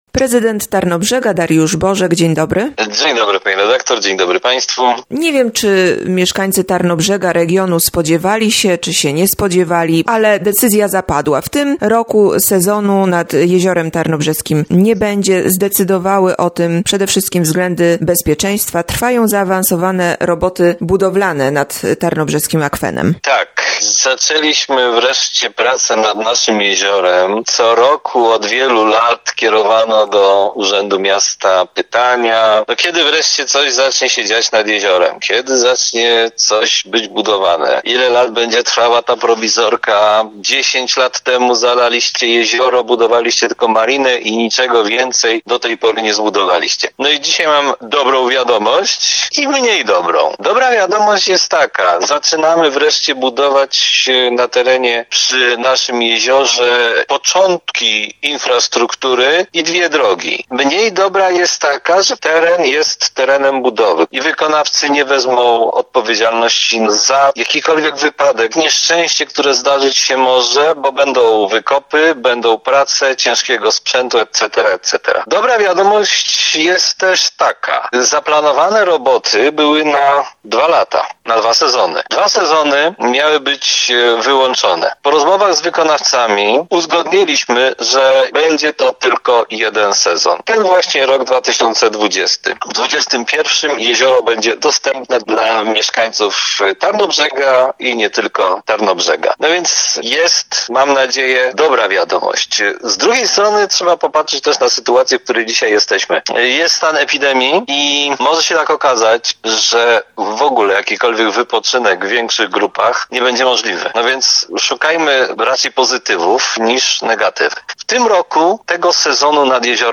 Nie będzie sezonu nad Jeziorem Tarnobrzeskim. Rozmowa z prezydentem miasta, Dariuszem Bożkiem.